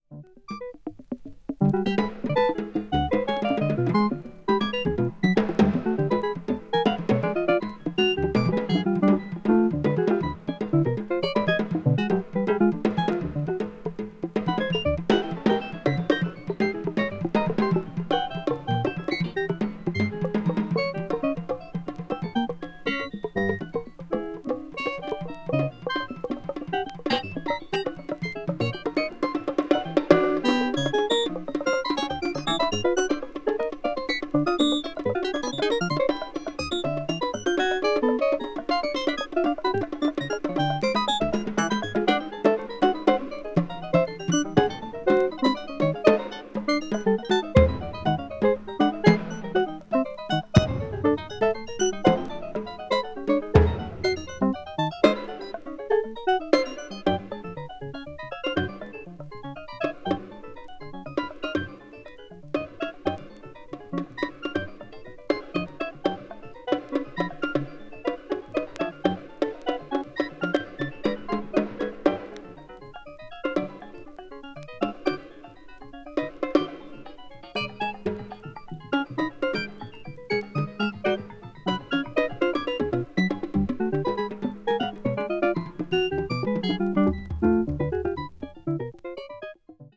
media : VG+/VG+(薄いスリキズによるわずかなチリノイズ/一部軽いチリノイズが入る箇所あり)
20th century   contemporary   electronic   experimental